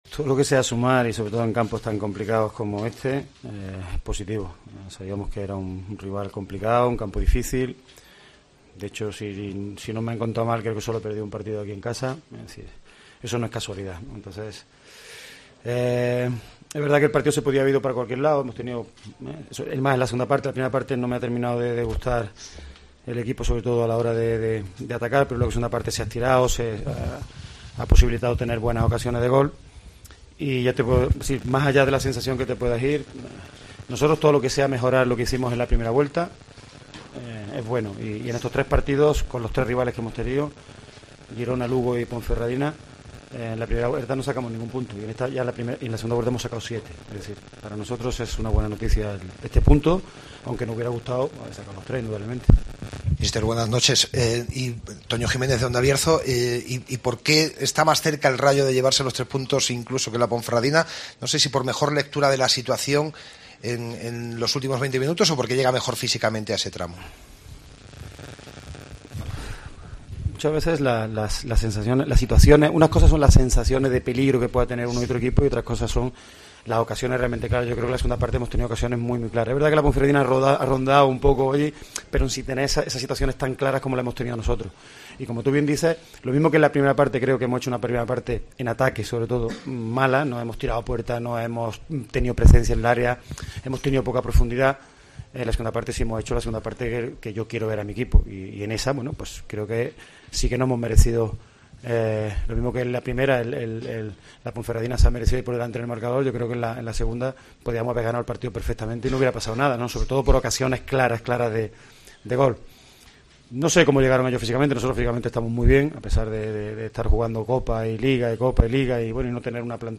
Escucha aquí el postpartido con declaraciones de Paco Jémez